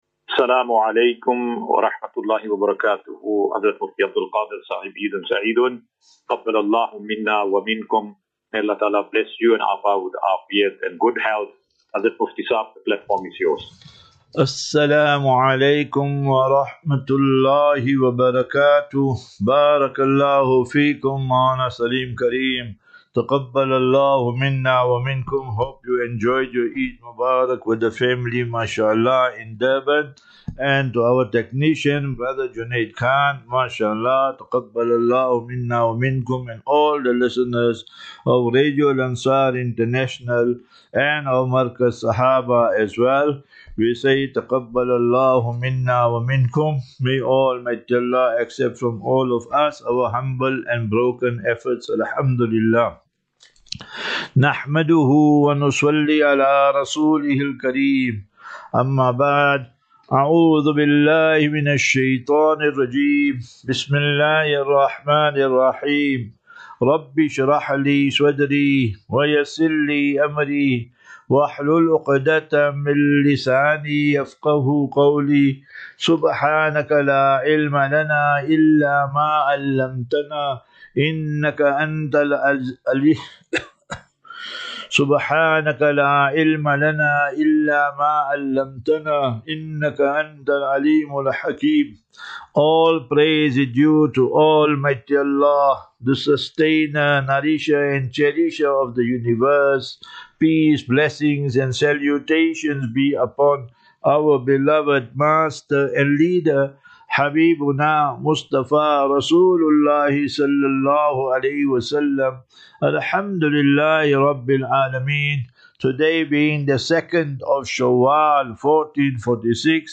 As Safinatu Ilal Jannah Naseeha and Q and A 1 Apr 01 April 2025.